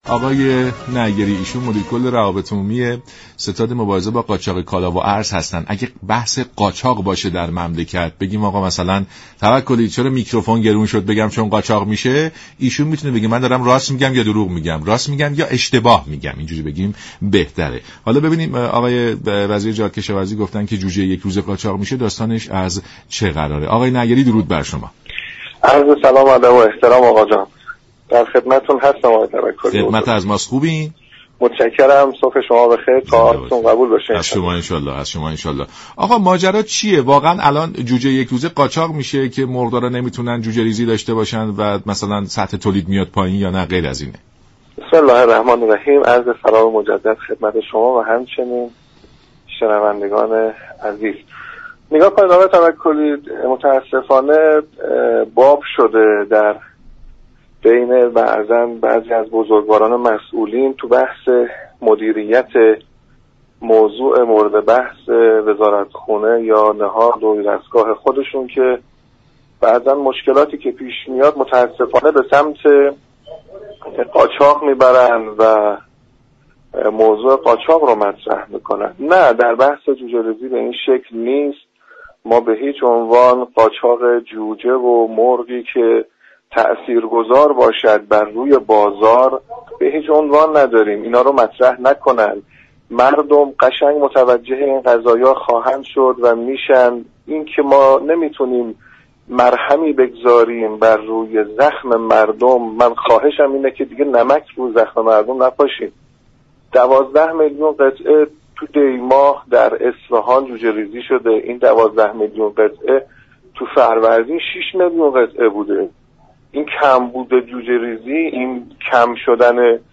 در برنامه سلام صبح بخیر رادیو ایران در پاسخ به پرسشی مبنی بر قاچاق جوجه یك روزه و پایین آمدن سطح تولید گفت